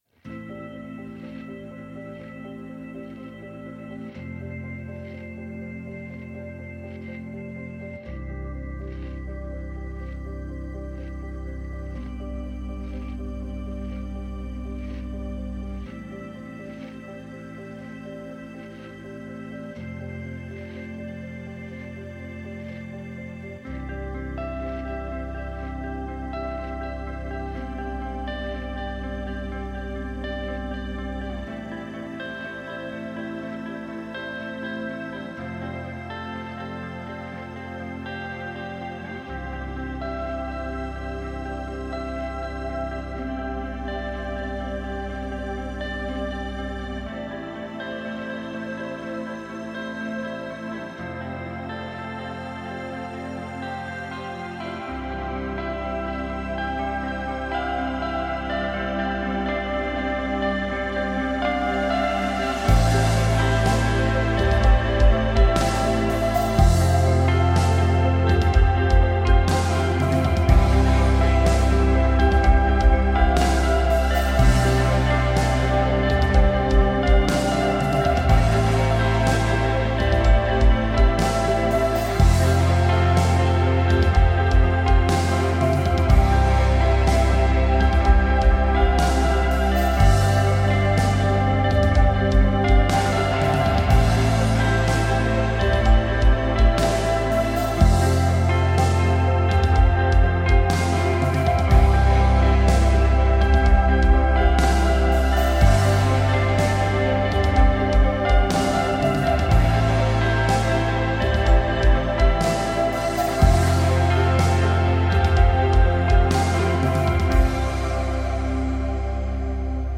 پست راک Post rock موسیقی اینسترومنتال